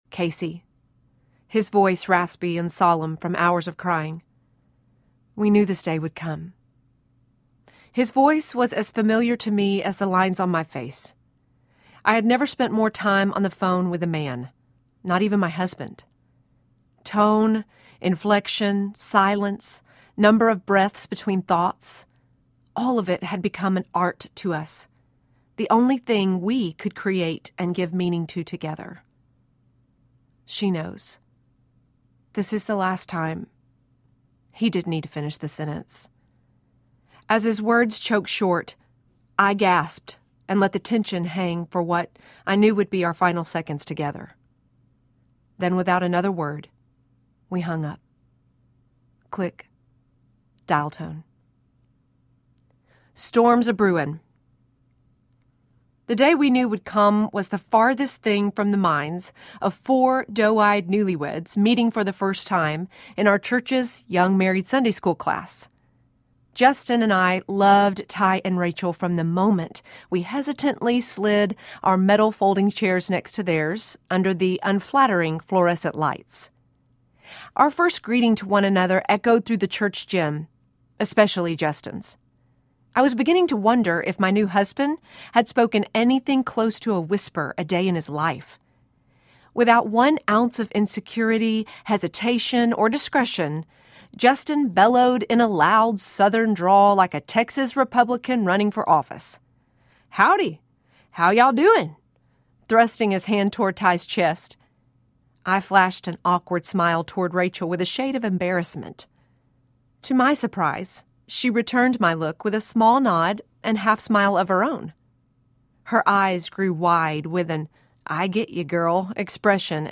Nothing Wasted Audiobook
5.3 Hrs. – Unabridged